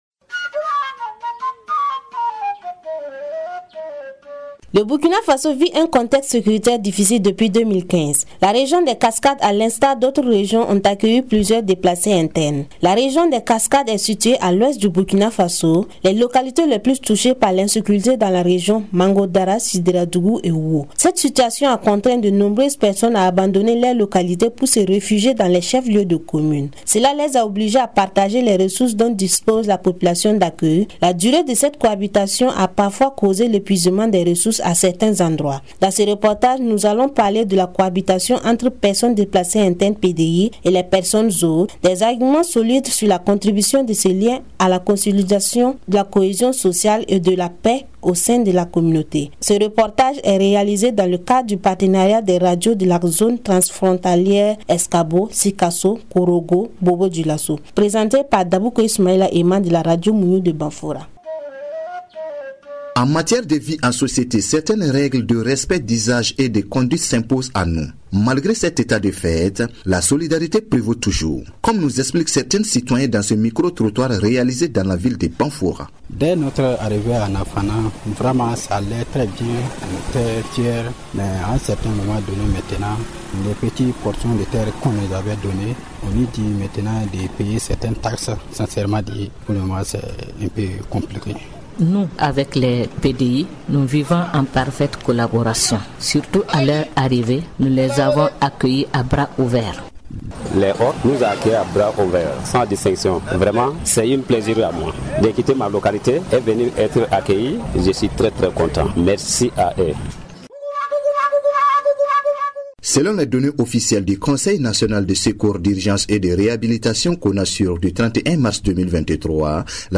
Dans ce reportage, nous explorerons la cohabitation entre les personnes déplacées internes et leurs hôtes, un lien fragile mais fondamental pour la cohésion sociale et la paix dans nos communautés. À travers un micro-trottoir, nous recueillerons les avis des citoyens sur le bon vivre ensemble. Nous reviendrons également sur les efforts de solidarité des populations hôtes, les défis rencontrés, mais aussi les solutions trouvées pour garantir une cohabitation pacifique.